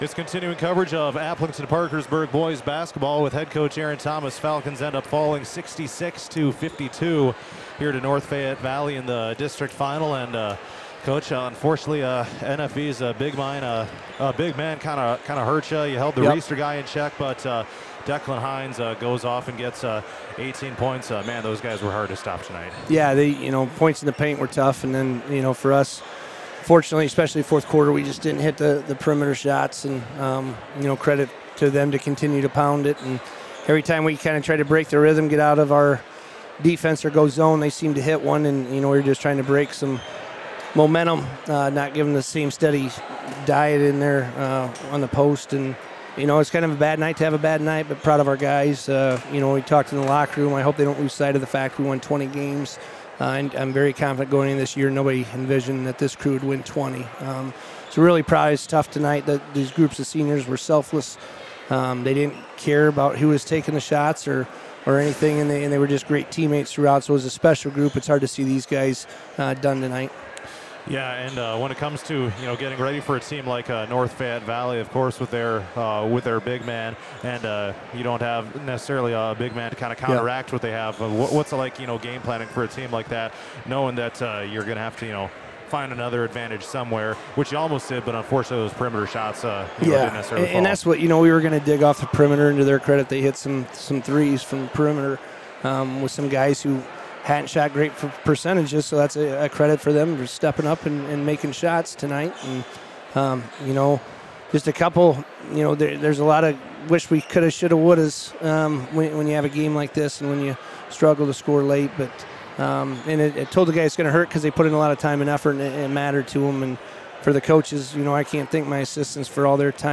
interview: